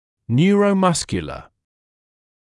[‘njuərə(u)’mʌskjulə][‘ньюро(у)’маскйэлэ]нервно-мышечный